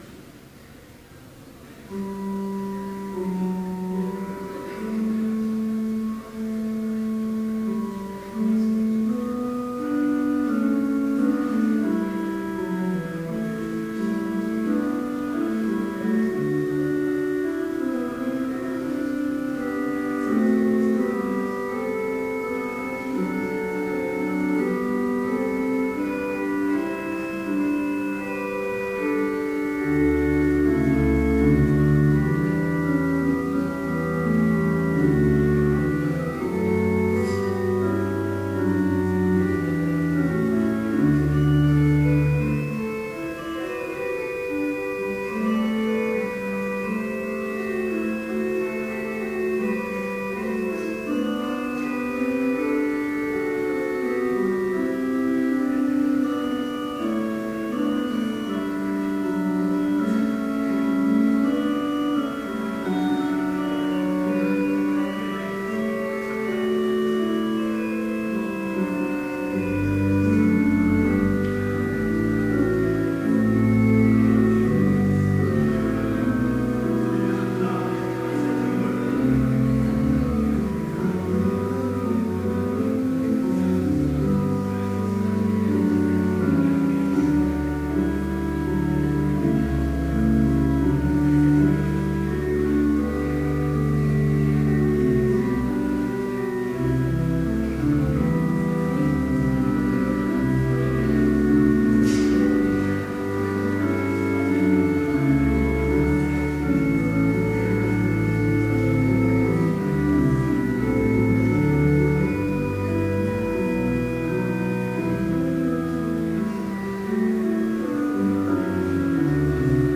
Complete service audio for Chapel - November 5, 2012
Order of Service Prelude Hymn 26, vv. 1 & 3, O Holy Ghost Reading: Deuteronomy 7:9-11 Homily Prayer Hymn 26, vv. 4 & 5, Thy gracious… Benediction Postlude